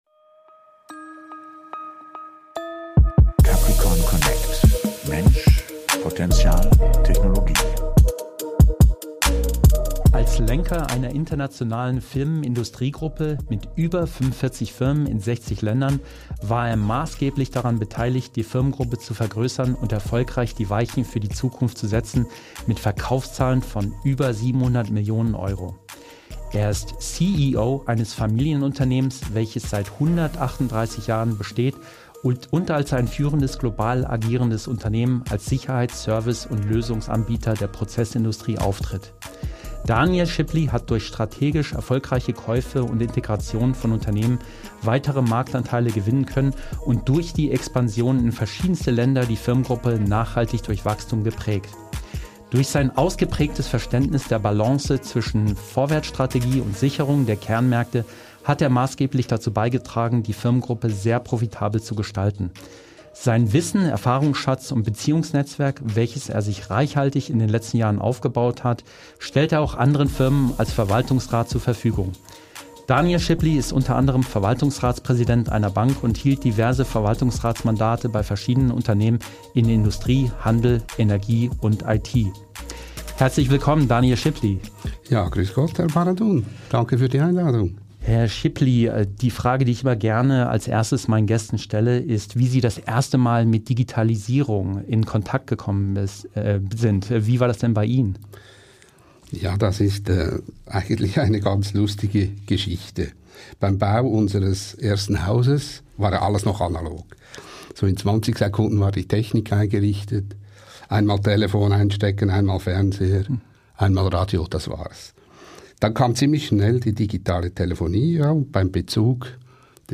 #12 - Interview